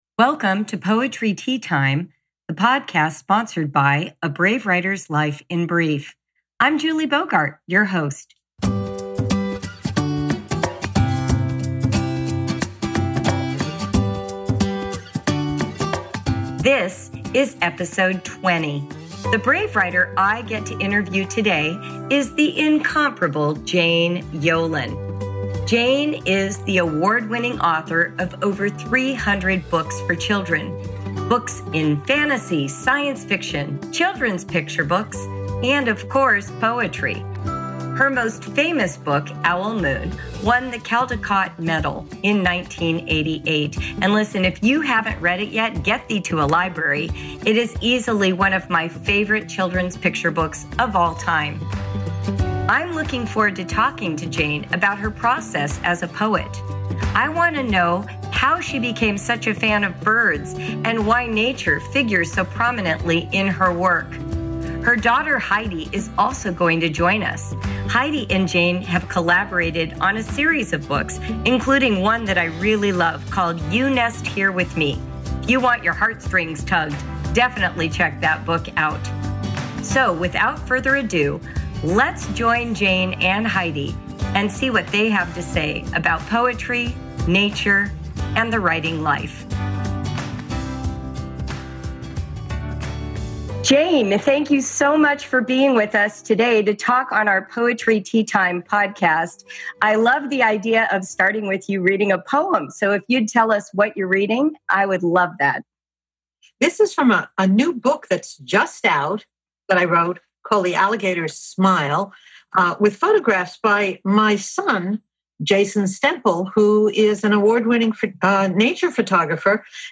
Poet Interview: Jane Yolen - Poetry Teatime